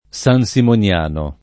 sansimoniano [ S an S imon L# no ]